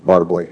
synthetic-wakewords
ovos-tts-plugin-deepponies_Barack Obama_en.wav